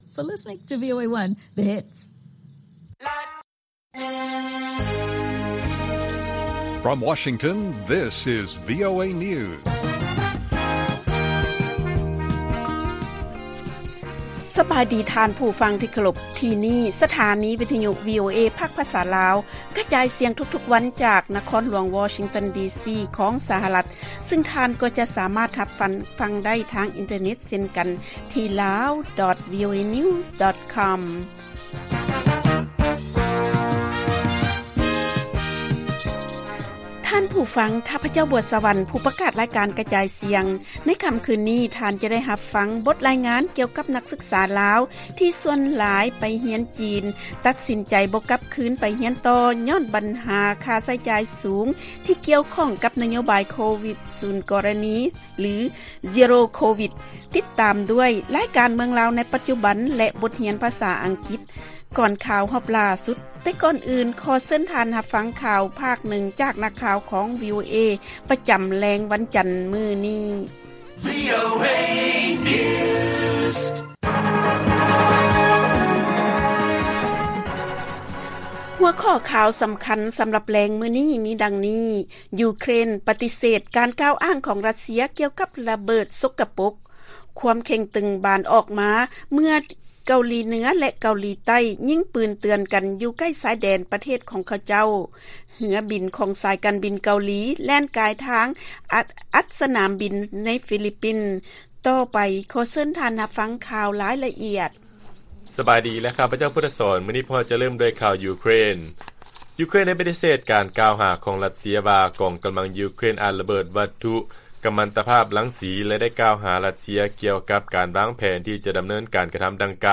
ລາຍການກະຈາຍສຽງຂອງວີໂອເອ ລາວ: ຢູເຄຣນ ປະຕິເສດການກ່າວອ້າງຂອງ ຣັດເຊຍ ກ່ຽວກັບ 'ລະເບີດສົກກະປົກ'